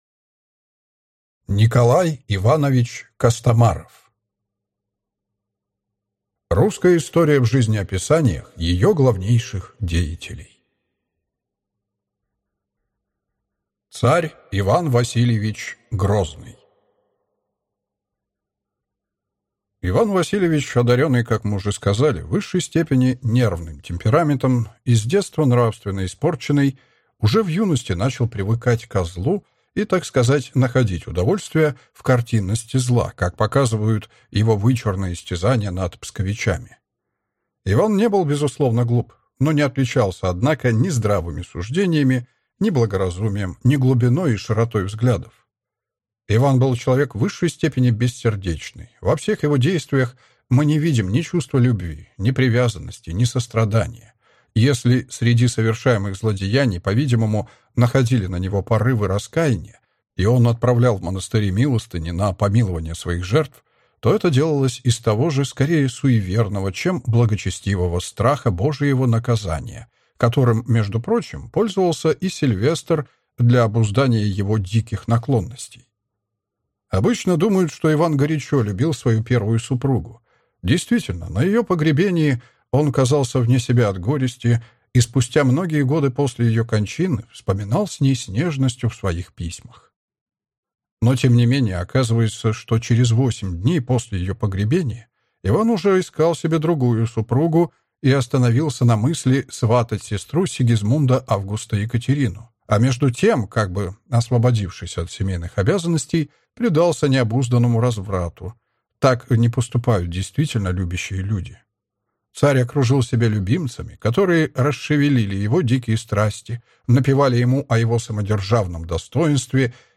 Аудиокнига Русская история в жизнеописаниях ее главнейших деятелей. Царь Иван Васильевич Грозный | Библиотека аудиокниг